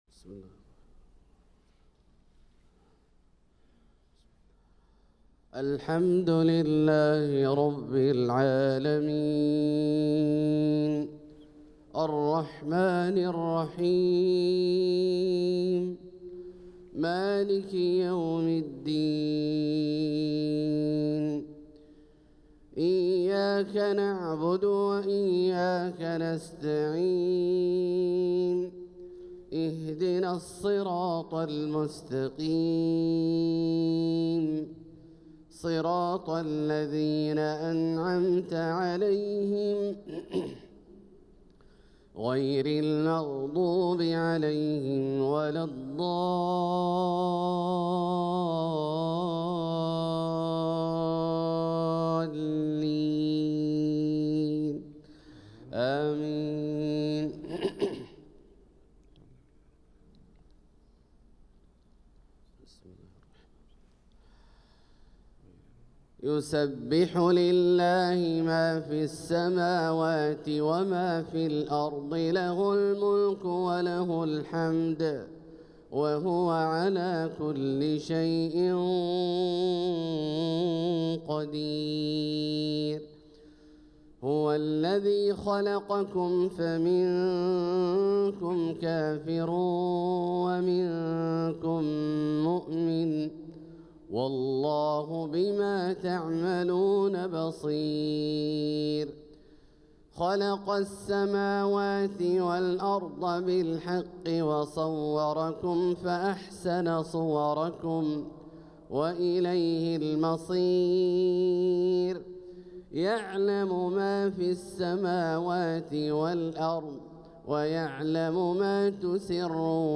صلاة الفجر للقارئ عبدالله الجهني 6 ربيع الأول 1446 هـ
تِلَاوَات الْحَرَمَيْن .